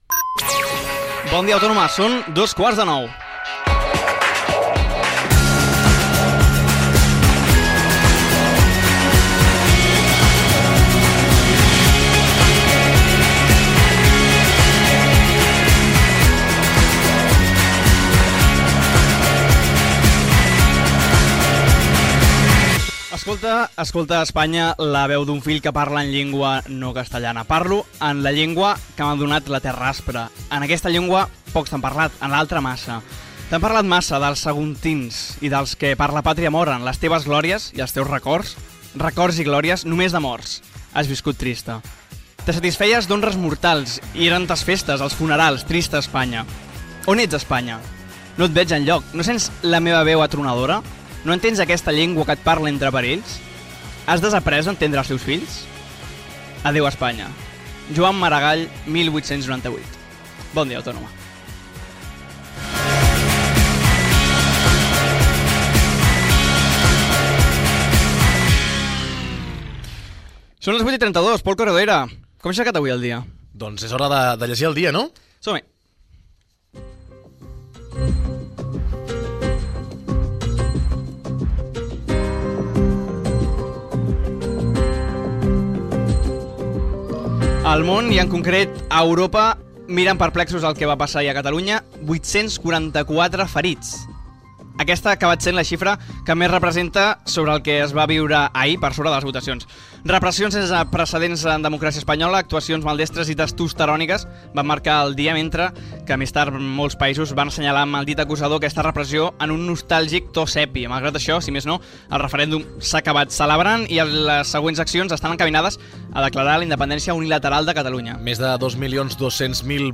Hora, poema de Joan Maragall, resum informatiu de la diada de l'1 d'octubre quan es va celebrar el referèndum d'autodeterminació unilateral, amb declaracions del president de la Generalitat Carles Puigdemont, el president Mariano Rajoy, etc. Gènere radiofònic Informatiu